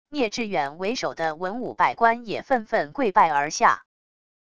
聂志远为首的文武百官也份份跪拜而下wav音频生成系统WAV Audio Player